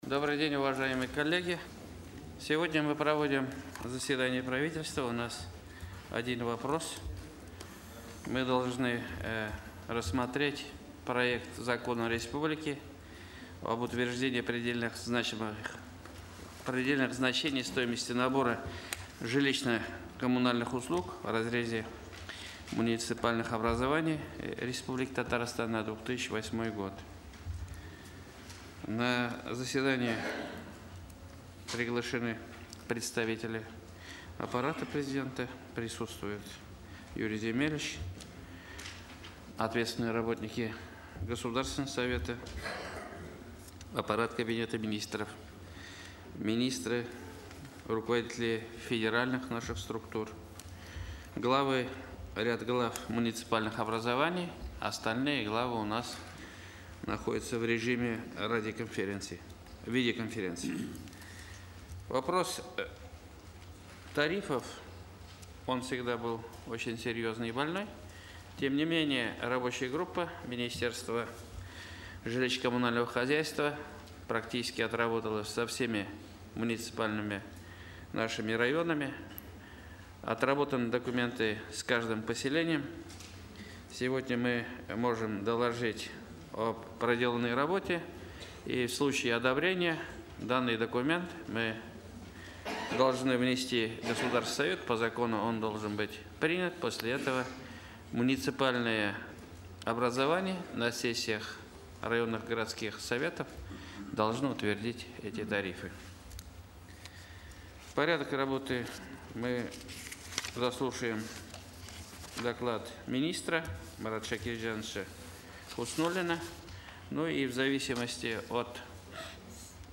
Аудиорепортаж